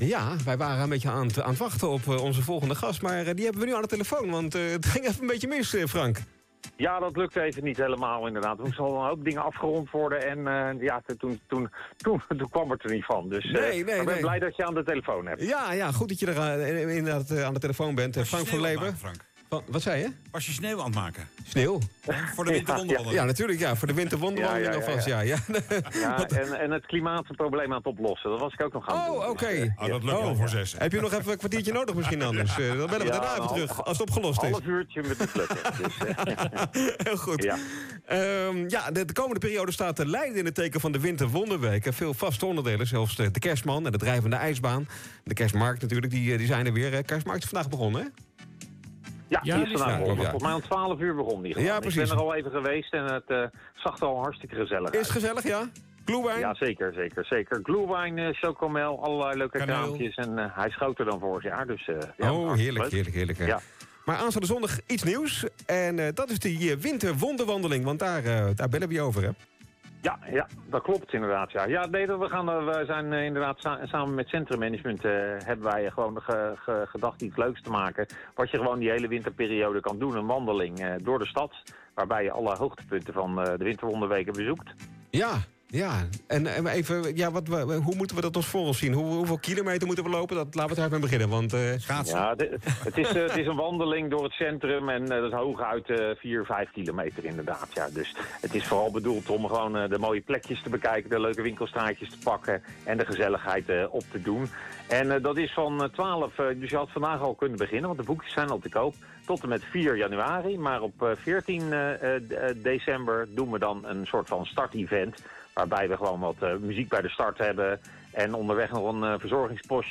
Interview Leiden Maatschappij Nieuws